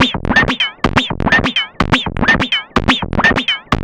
tx_perc_125_outacontrol1.wav